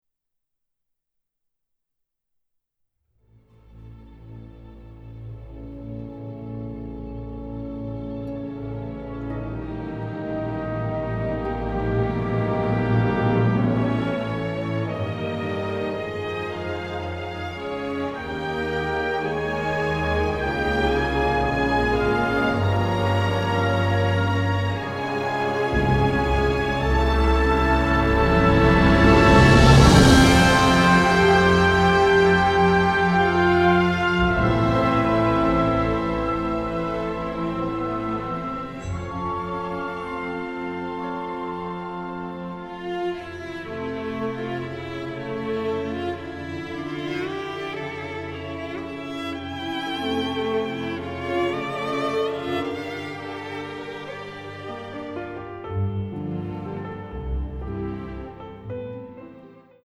delightfully emotional
full of beautiful and delicate melodies